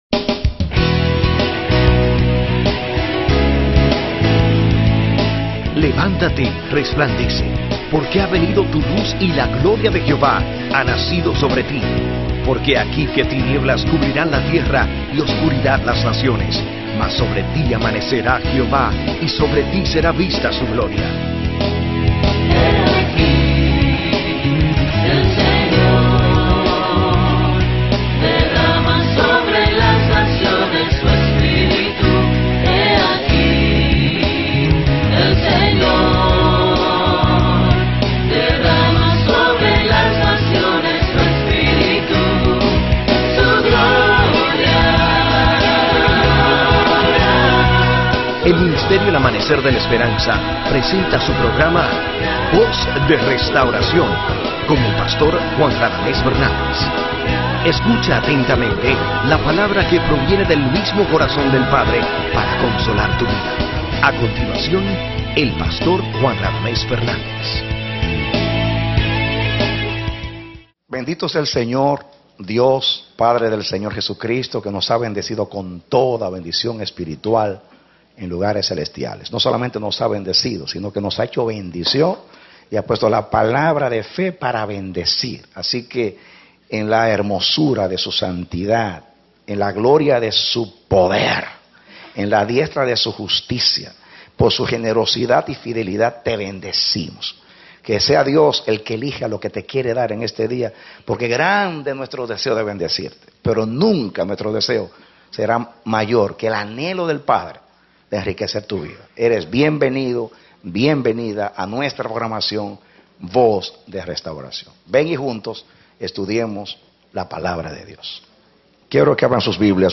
Serie de 7 Predicado Febrero 19, 2012